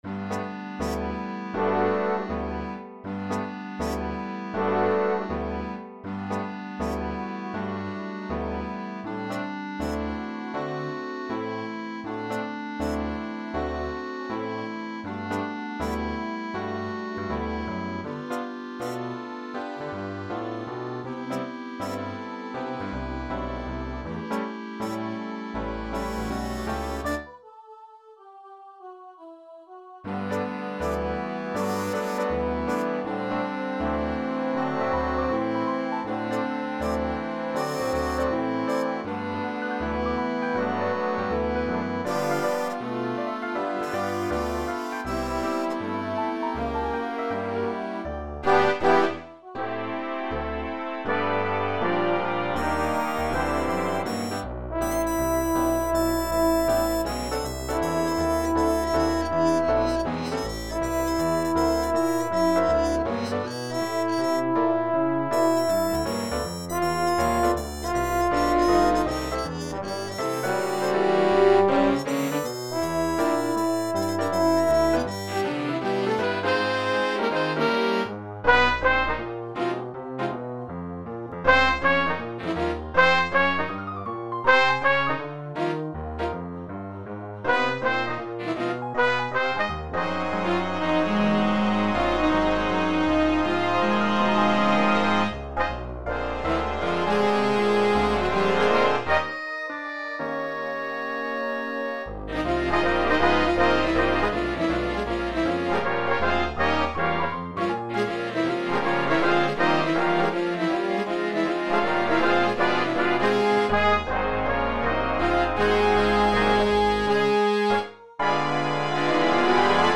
Vocal, swing